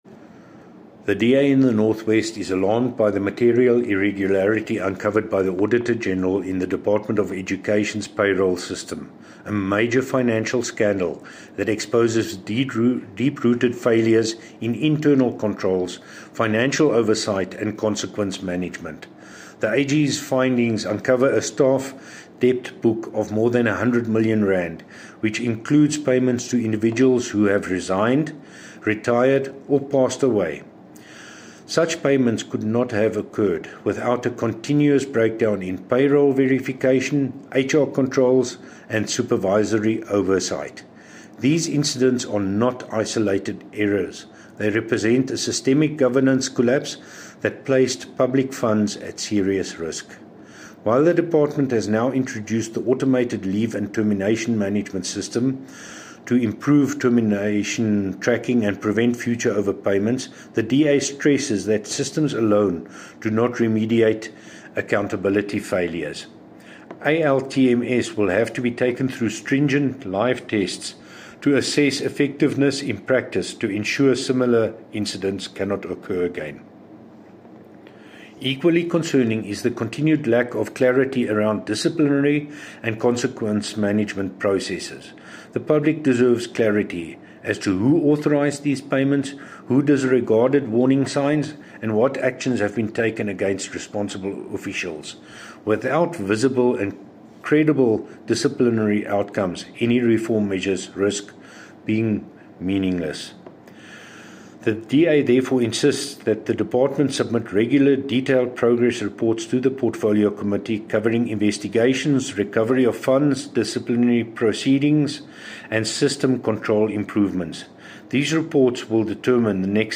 Note to Broadcasters: Please find the attached soundbites in